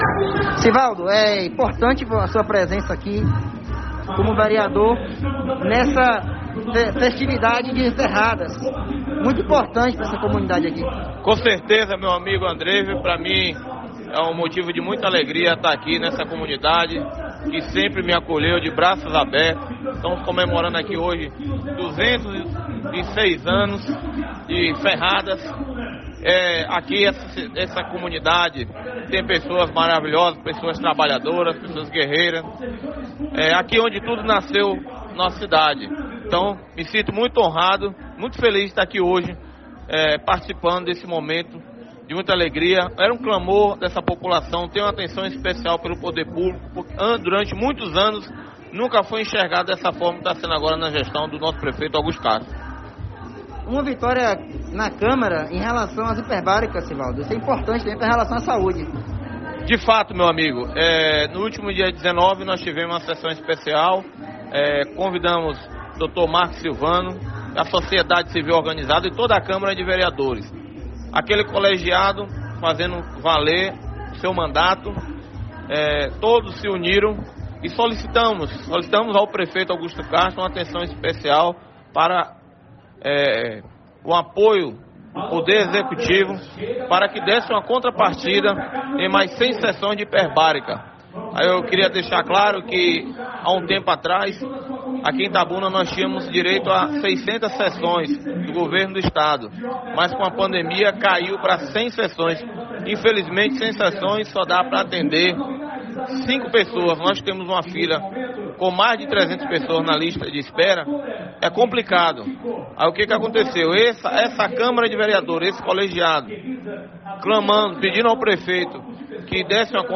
Durante entrevista, os edis também destacaram a vitória conquistada após sessão especial, que cobrou a ampliação de sessões para tratamento contra feridas crônicas.